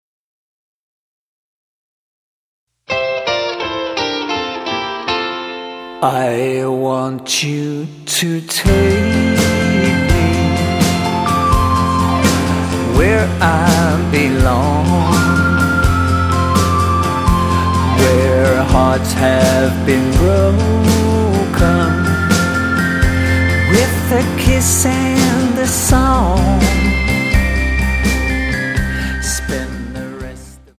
--> MP3 Demo abspielen...
Tonart:F Multifile (kein Sofortdownload.